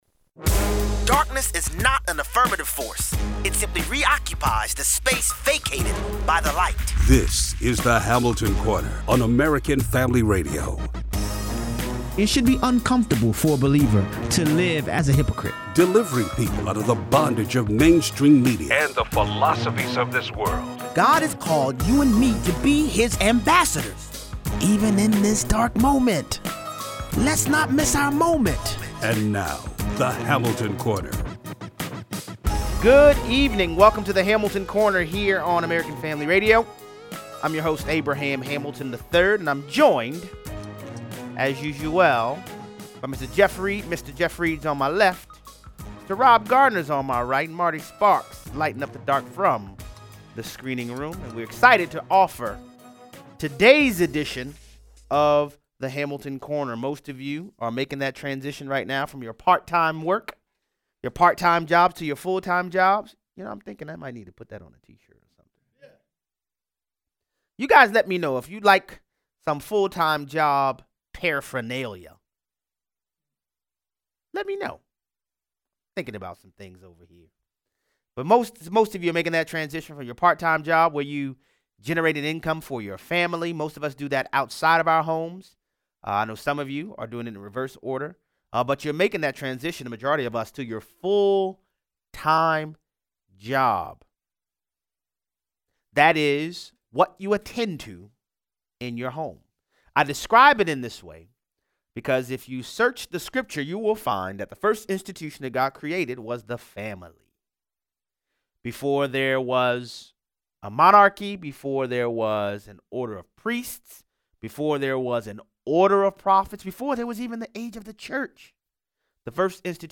Democrat power-brokers want to shut down the presidential primary, but Bernie Sanders says he’s still in it. Callers weigh in.